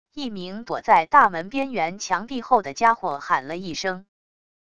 一名躲在大门边缘墙壁后的家伙喊了一声wav音频